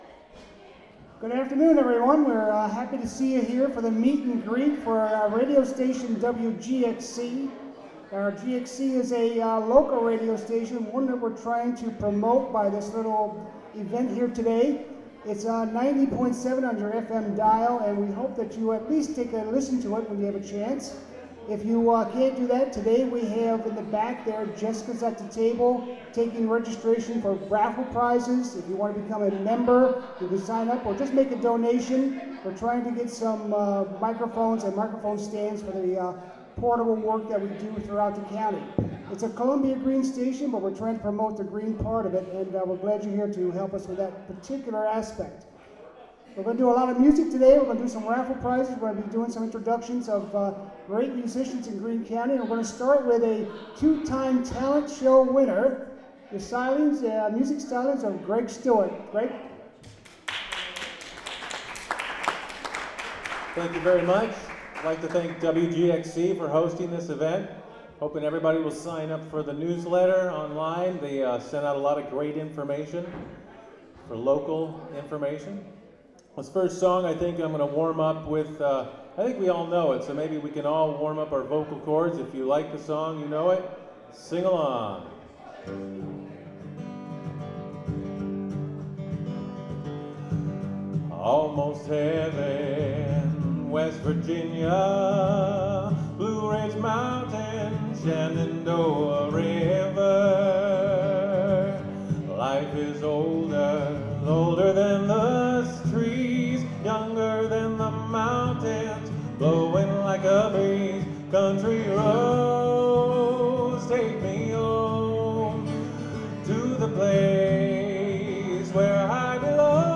Highlights from the Shamrock House Meet & Greet. (Audio)